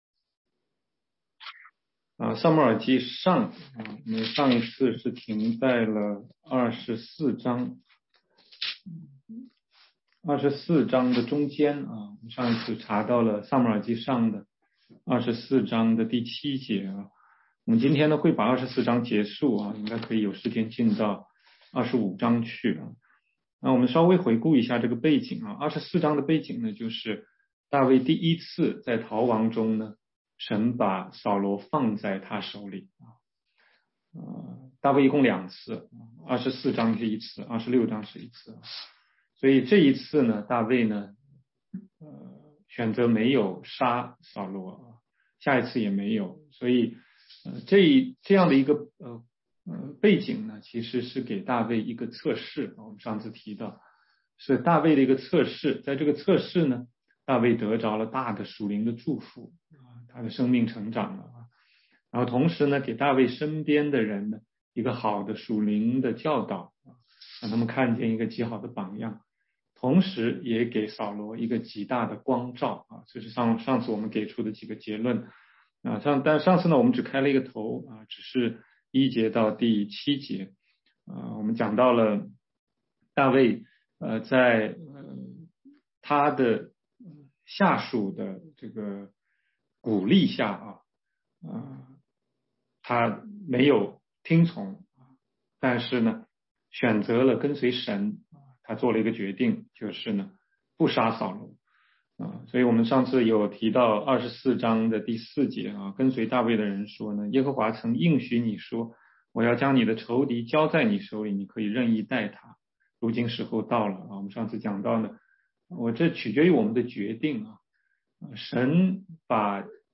16街讲道录音 - 撒母耳记上24章8-22节：扫罗对大卫不杀之恩的回应
全中文查经